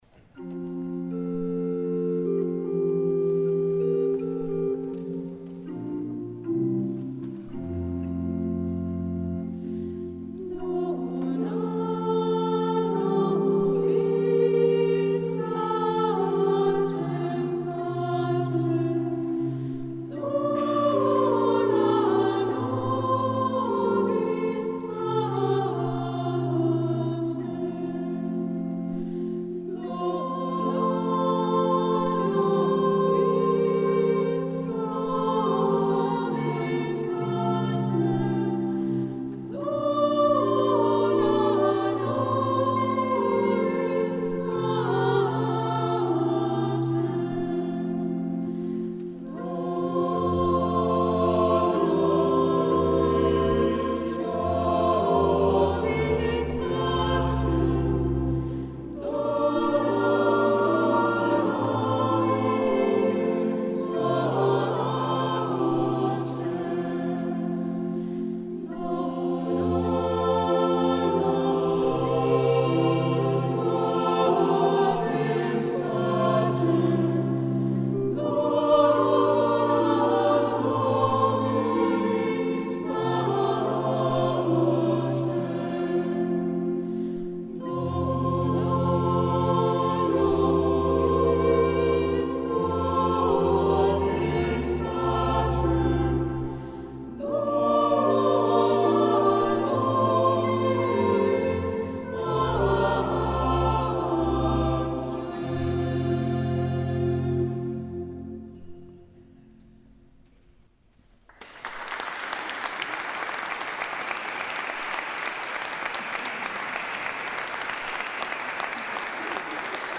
Canone
Luogo esecuzioneSanta Maria del Quartiere -Parma
GenereCori